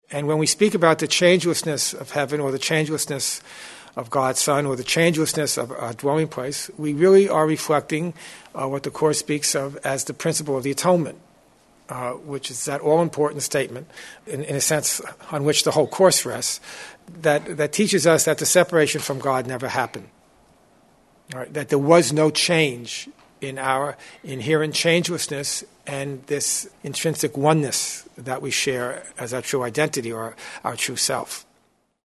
Changelessness is the theme of this workshop, based on the section from Chapter 29 in the text. This theme is discussed on two levels: 1) Our changeless dwelling place in Heaven and its memory in our right minds. 2) How to reflect the changelessness of God’s Son in our relationships within the dream.